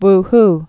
(wh)